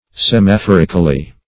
Semaphorically \Sem`a*phor"ic*al*ly\, adv.
semaphorically.mp3